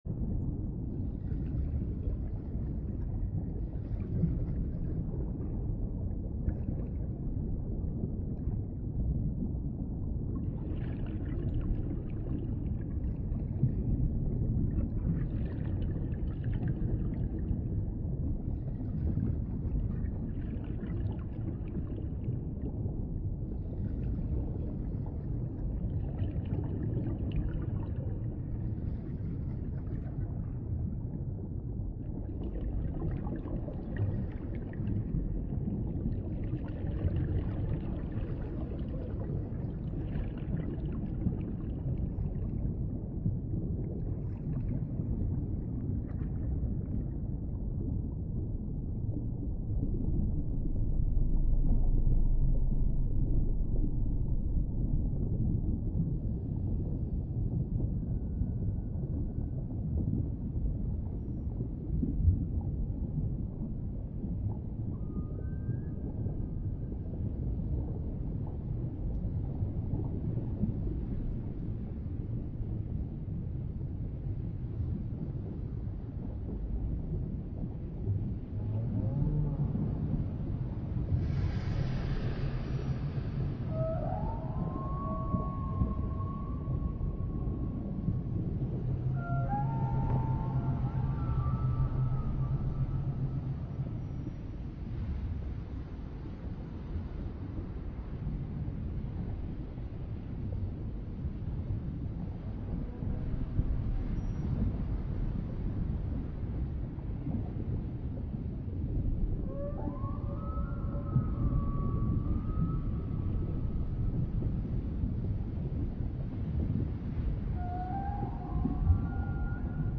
Horrors of the Depths Dark Ambient Underwater Sea Monster Sounds Use Headphones (8D Sounds)